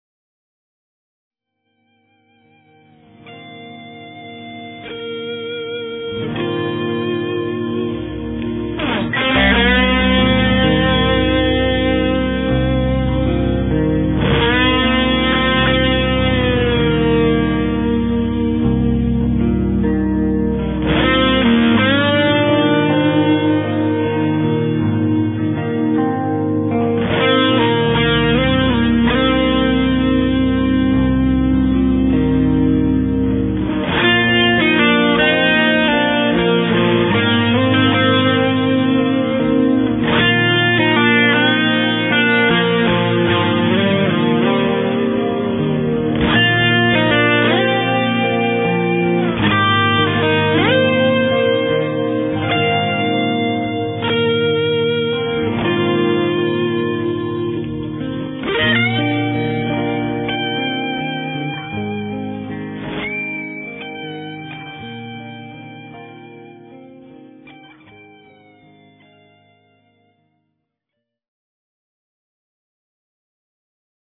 When heavy distortion is called for, the Heartbreaker will oblige with a classic rock distortion tone which stays weighty and full sounding, laced with harmonic richness but never becoming fizzy.
Heavy distortion with gain, bass and treble set high. The guitar used is a Jackson tuned down to C.